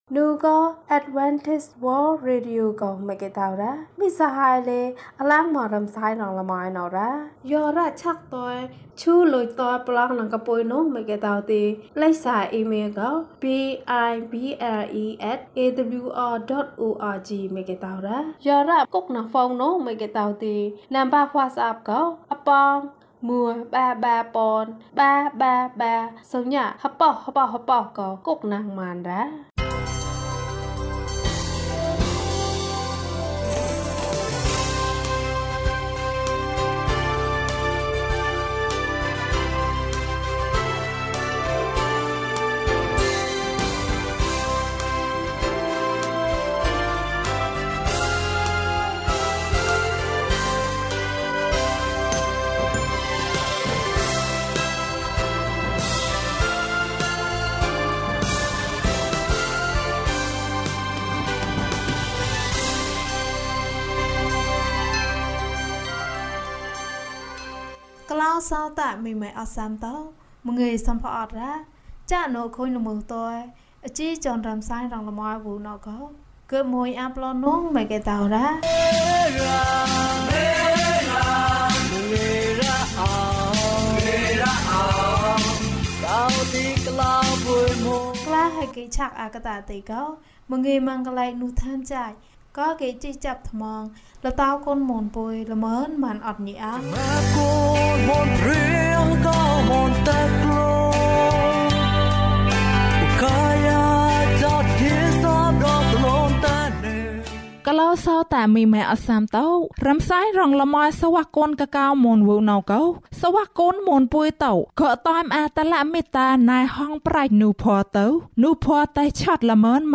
အံ့သြဖွယ်ဘုရားသခင်။၀၂ ကျန်းမာခြင်းအကြောင်းအရာ။ ဓမ္မသီချင်း။ တရားဒေသနာ။